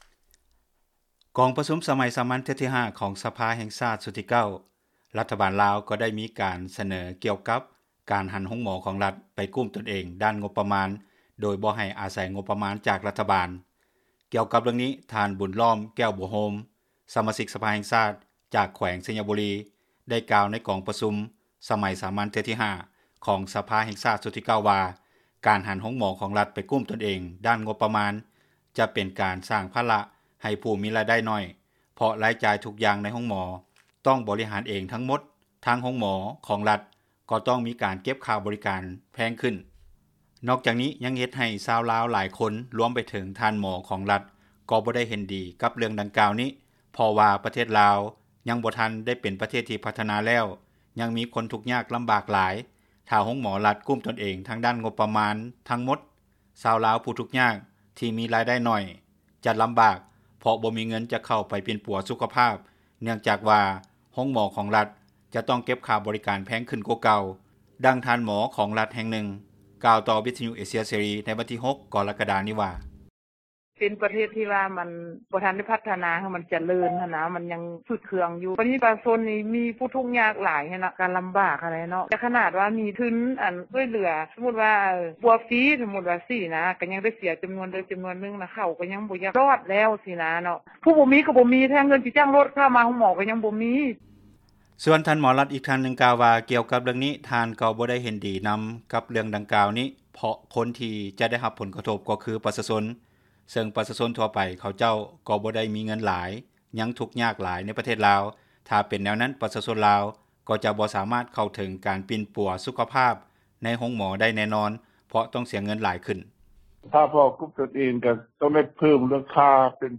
ດັ່ງທ່ານໝໍ ຂອງຣັຖແຫ່ງໜຶ່ງ ກ່າວຕໍ່ວິທຍຸ ເອເຊັຽ ເສຣີ ໃນວັນທີ 06 ກໍຣະກະດານີ້ວ່າ: